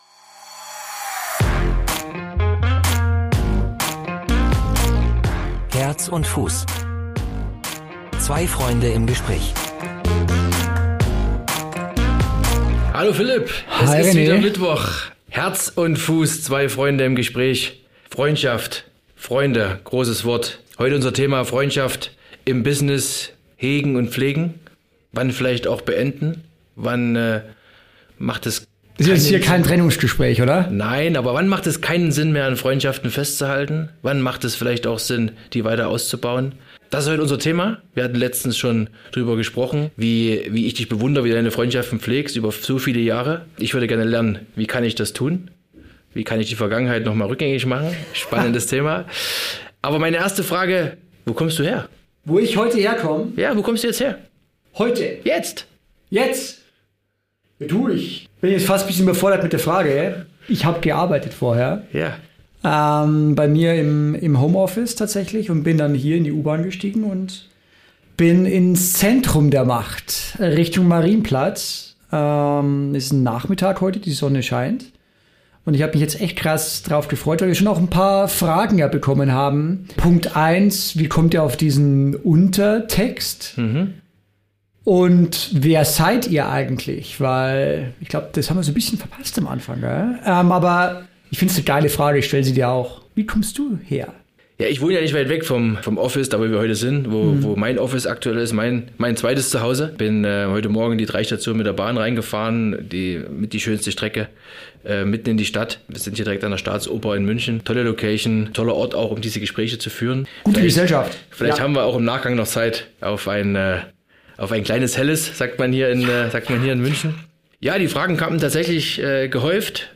Das und noch viel mehr hört ihr in dieser Folge von “Herz & Fuß" – Zwei Freunde im Gespräch.